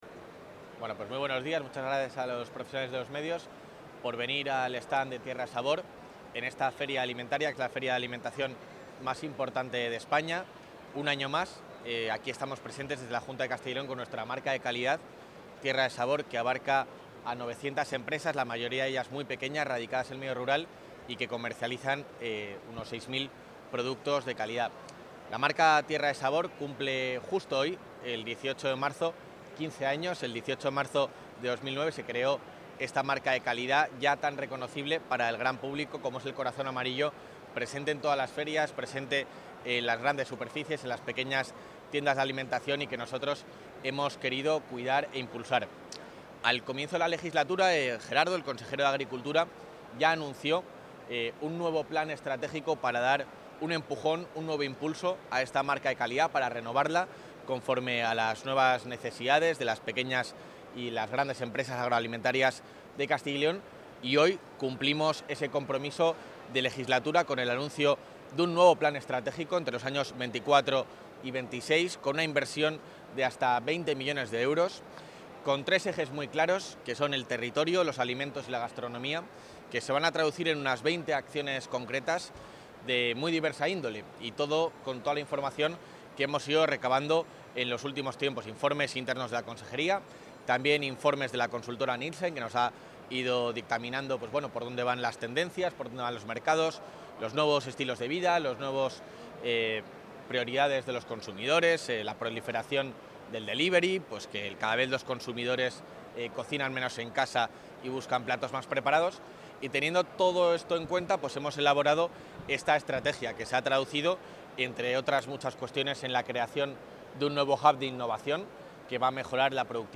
Valoraciones del vicepresidente de la Junta.